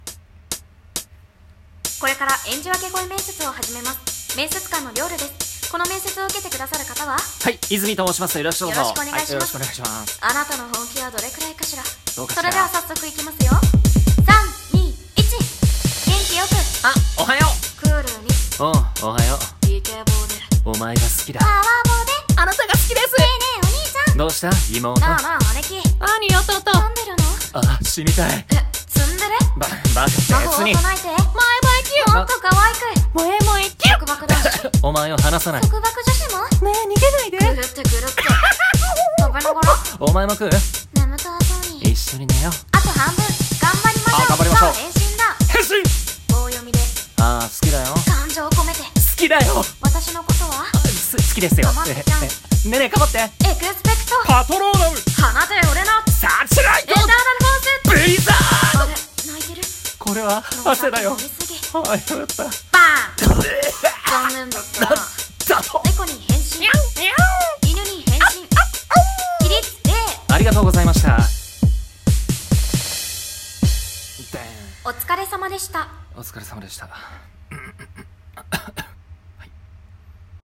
【声面接】演じ分け声面接